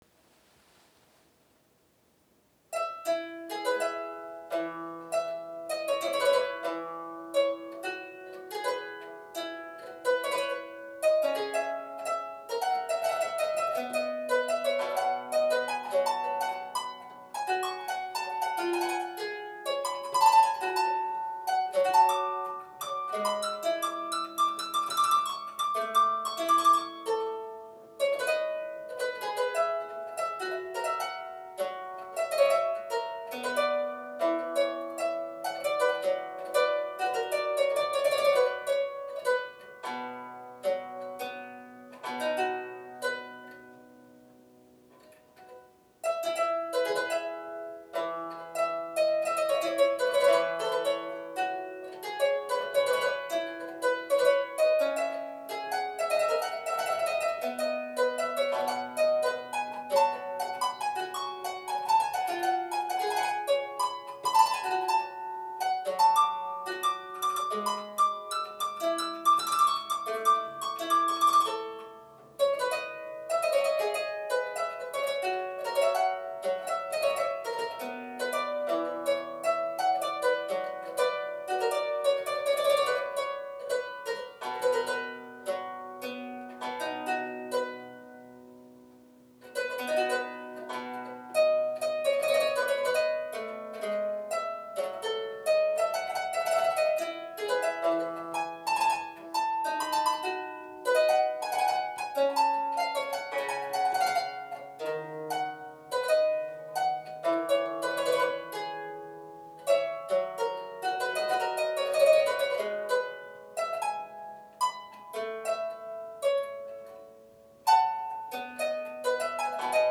3' Ottavino Lautenwerk - 2x4' featuring an unprecedented action consisting of one rank of jacks plucking one or both choirs with single plectra.